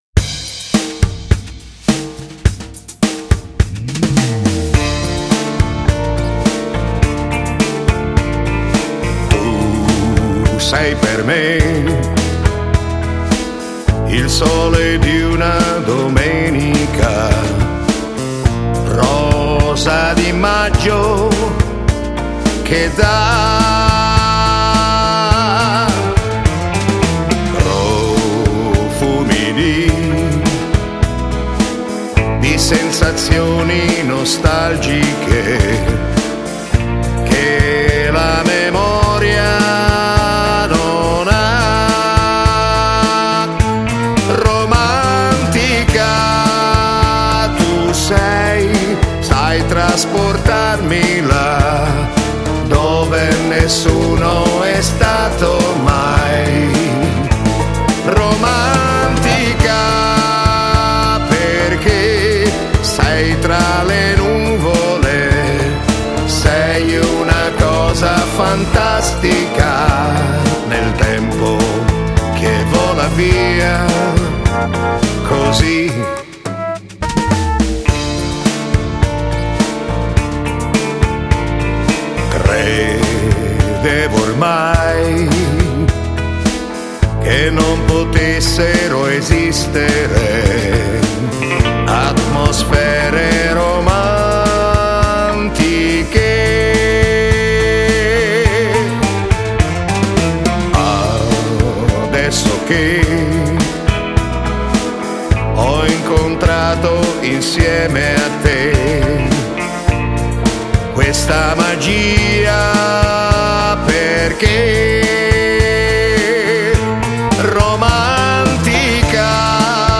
Genere: Moderato Lento